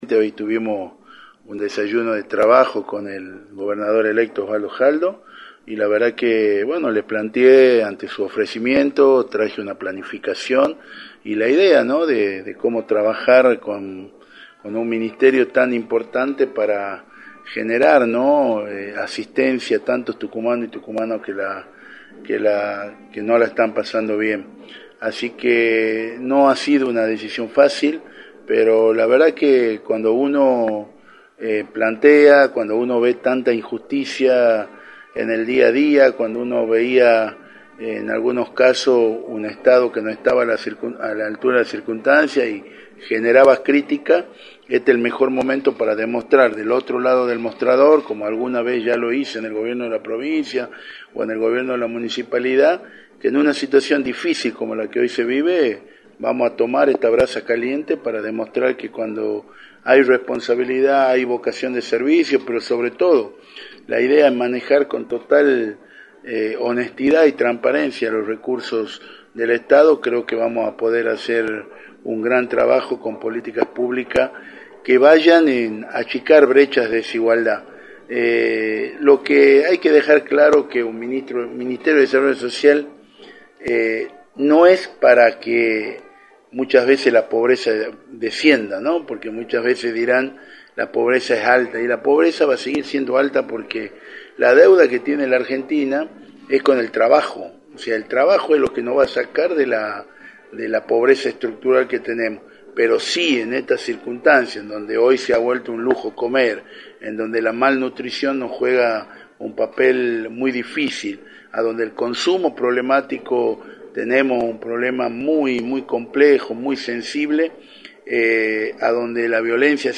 Federico Masso, Legislador por Libres del Sur, confirmó en Radio del Plata Tucumán, por la 93.9, que asumirá como Ministro de Desarrollo Social de la provincia, luego de aceptar la propuesta del Gobernador electo, Osvaldo Jaldo.